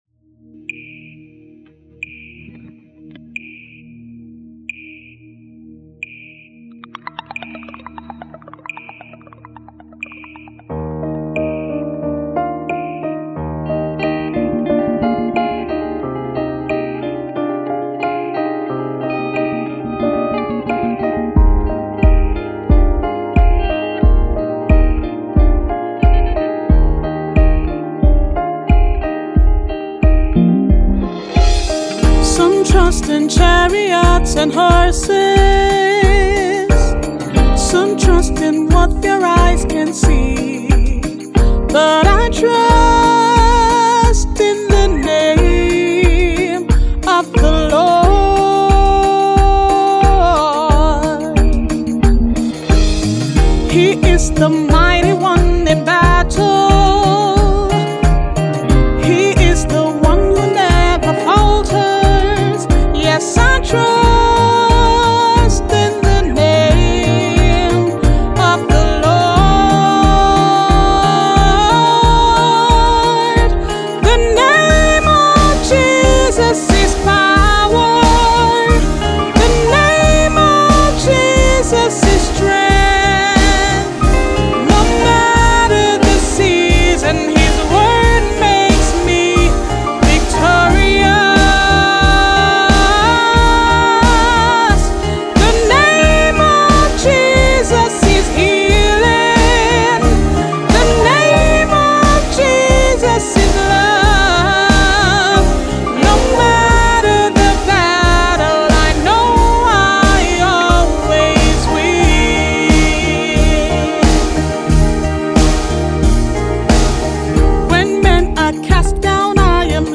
Nigerian gospel song